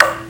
normal-hitnormal2.ogg